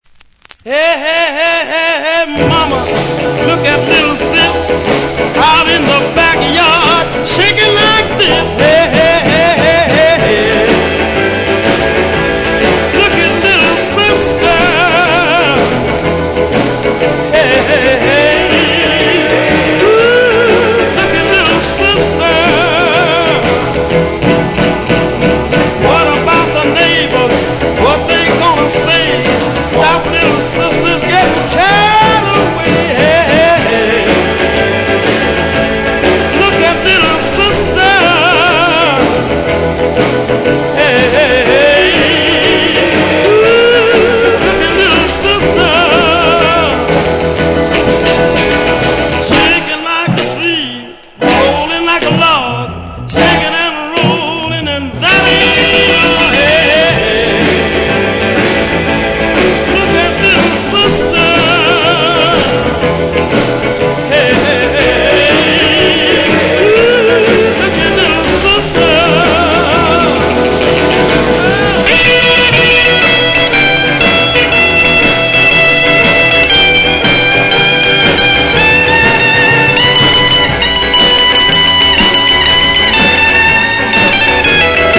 Genre: SOUL ORIG / REISS